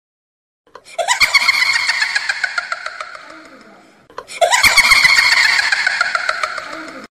Download Witch sound effect for free.